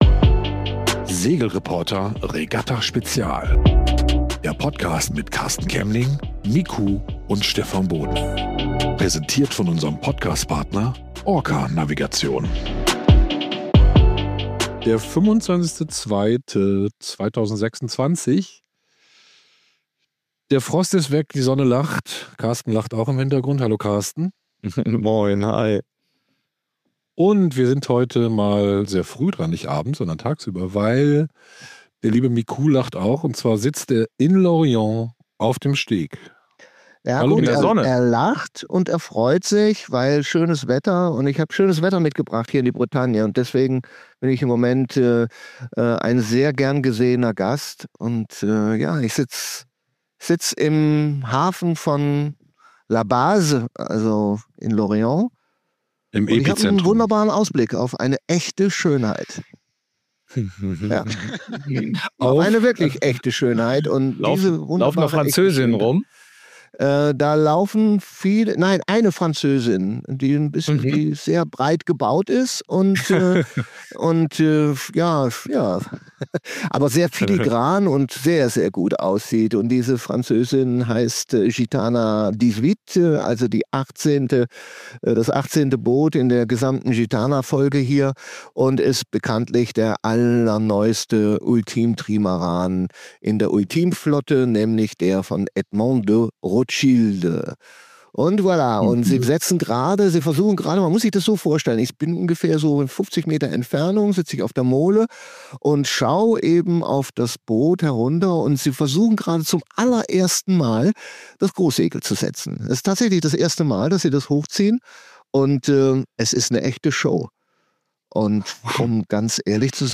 Regatta Spezial: Live vom Steg aus Lorient ~ Der SegelReporter-Podcast Podcast